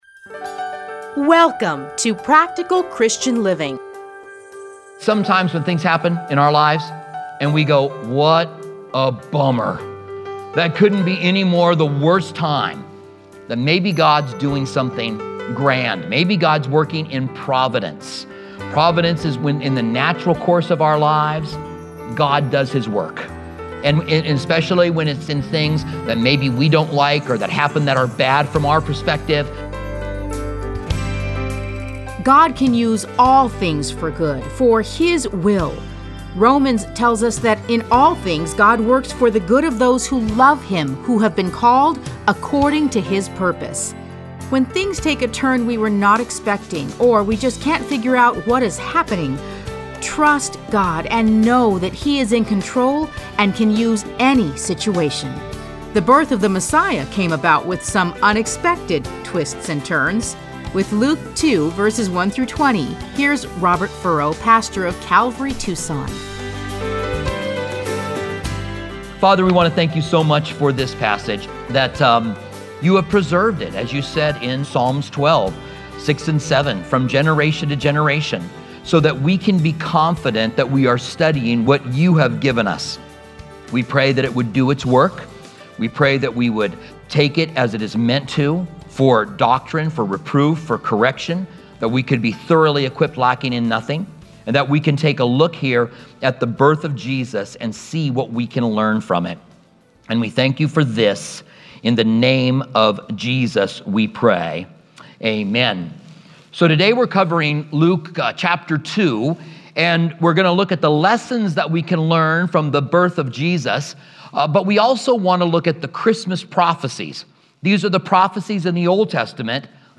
Listen to a teaching from Luke 2:1-20.